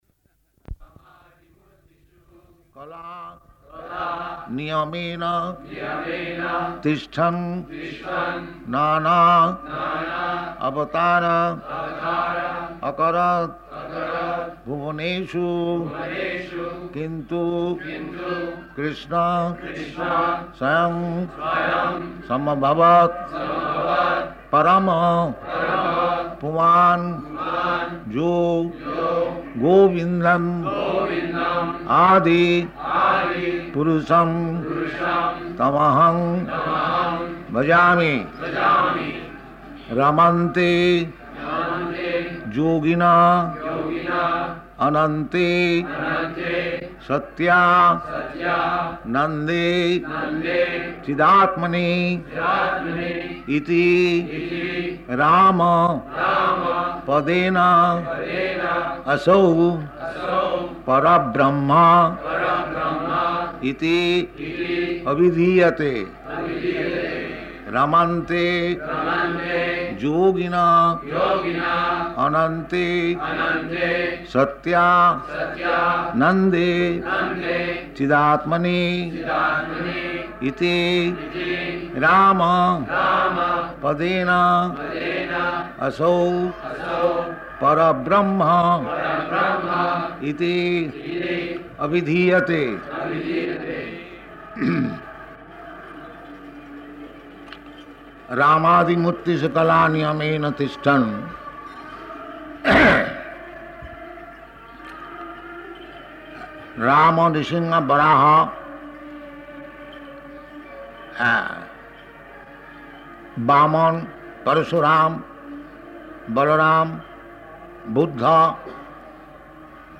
Location: Bombay
[leads chanting of verses]